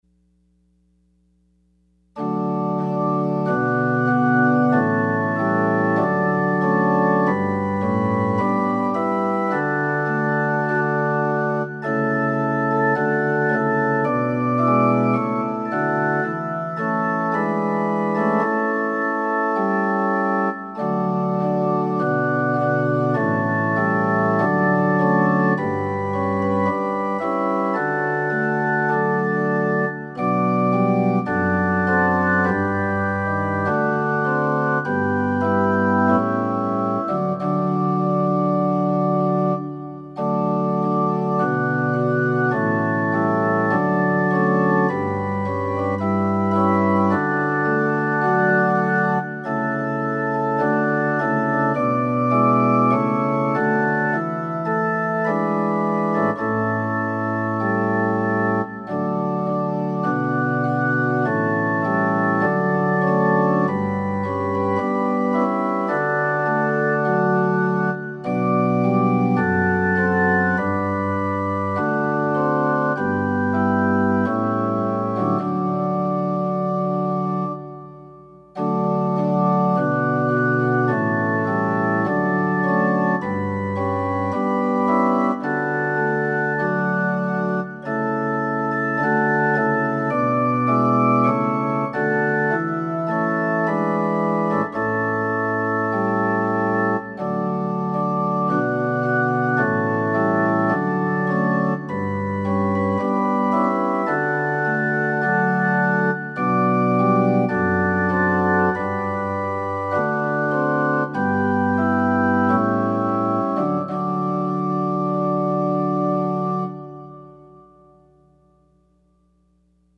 Offering Hymn – Holy, holy, holy! #362 (verses 1,3,4)